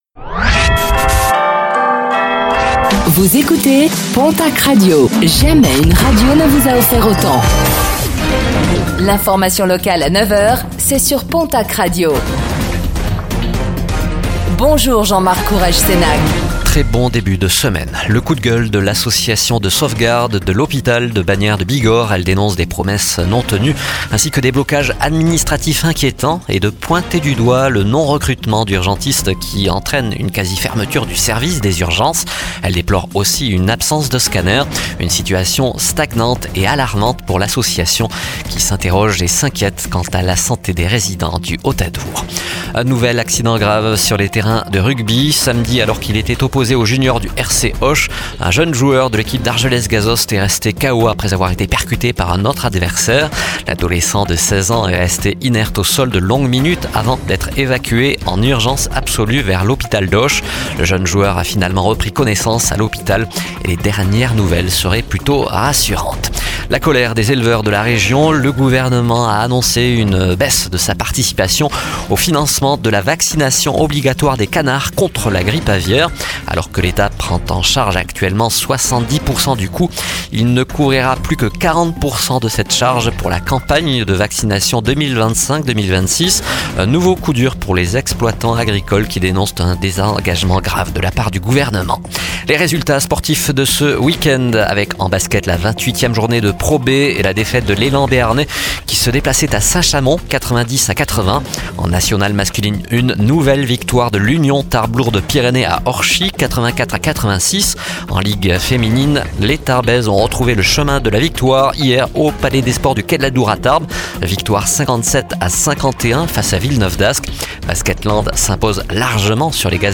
Réécoutez le flash d'information locale de ce lundi 17 mars 2025